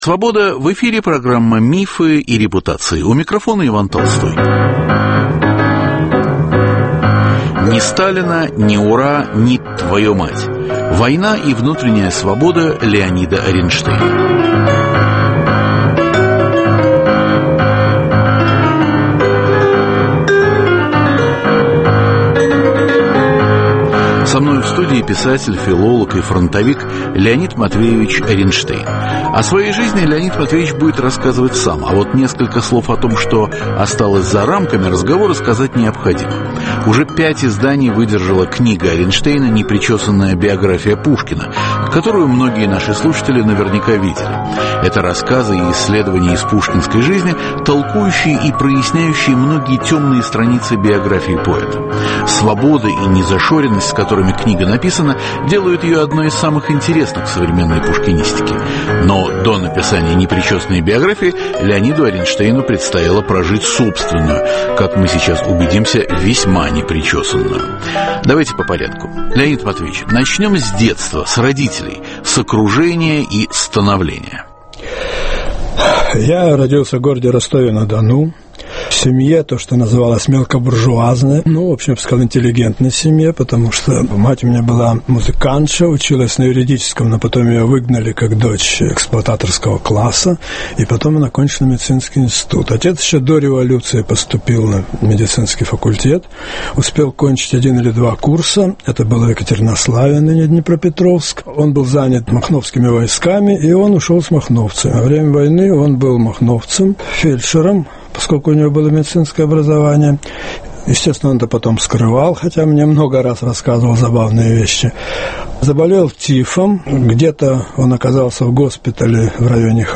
В студии - писатель, филолог и фронтовик